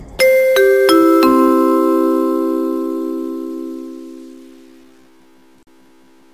Download Nada Bel Sekolah Otomatis “Penutup” – Suara khas bel sekolah ala Indo yang bikin nostalgia!
Nada Bel Sekolah Otomatis “Penutup”
Genre: Efek suara Tag: Efek suara , Nada Bel Sekolah , nada dering lucu Ukuran file: 520 KB Dilihat: 2965 Views / 48 Downloads Detail: Yuk cobain Nada Bel Sekolah khas Indonesia ini! Dengan efek suara penutup kelas yang ikonik, bikin chat masuk jadi nostalgia masa sekolah.
nada-bel-sekolah-otomatis-penutup.mp3